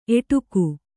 ♪ eṭuku